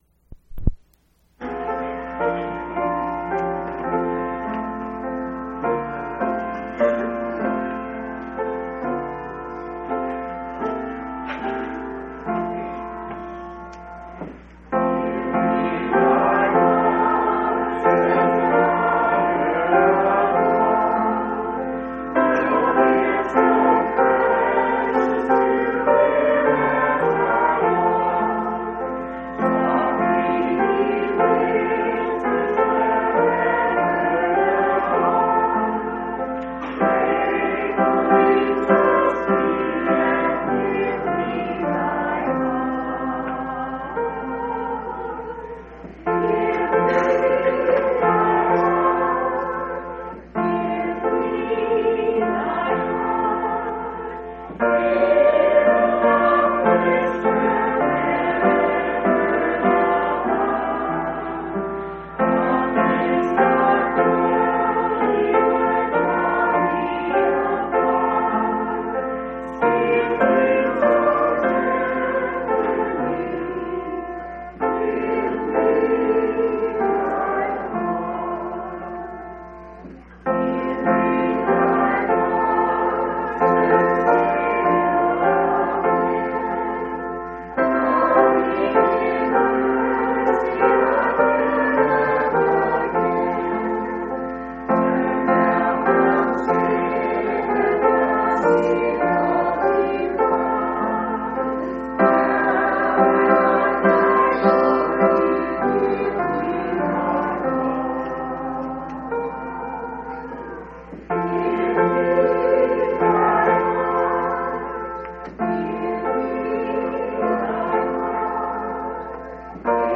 3/25/1990 Location: Phoenix Local Event